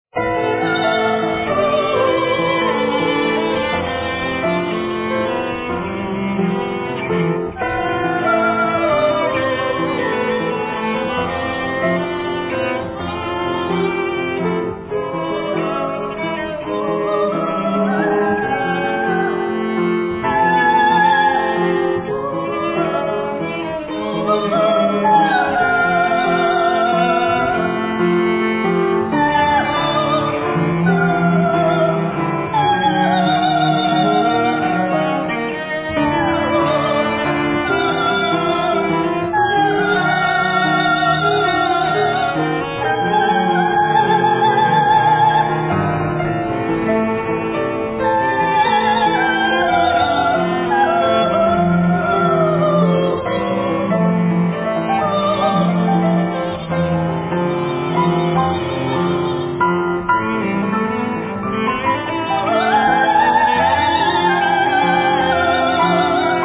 soprano
cello
piano, vocals